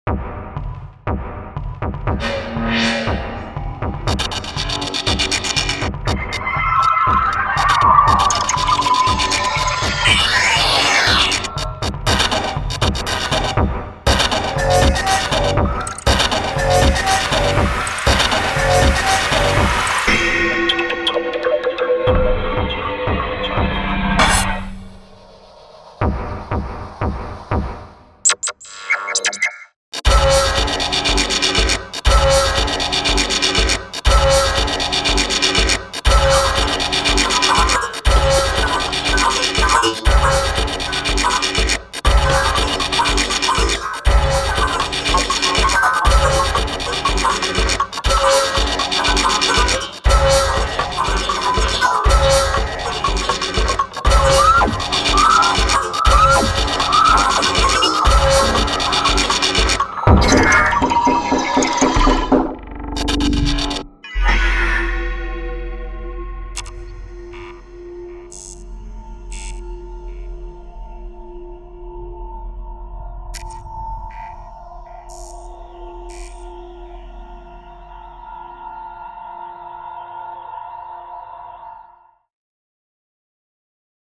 While The Resonant Drop does contain some pretty and shimmering deep space ambiences, it is mostly intended for that more disconcerting, cyberpunk, perhaps even downright scary future-vision.
Demos: